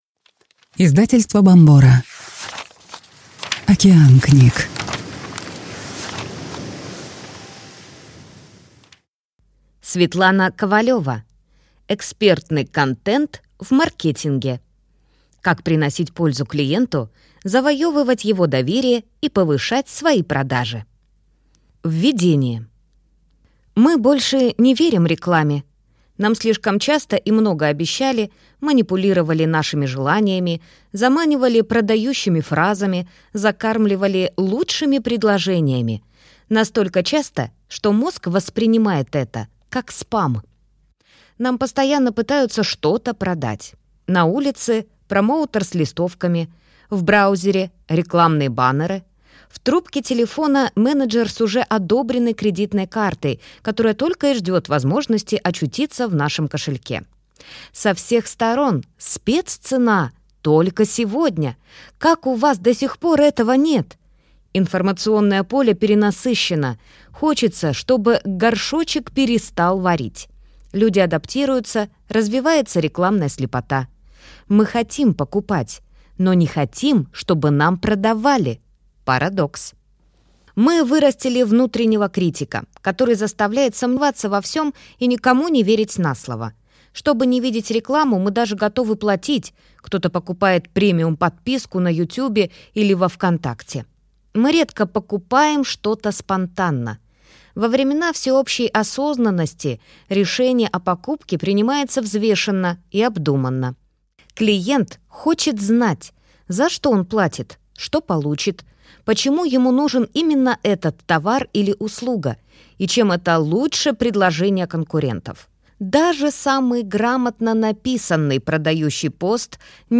Аудиокнига Экспертный контент в маркетинге. Как приносить пользу клиенту, завоевывать его доверие и повышать свои продажи | Библиотека аудиокниг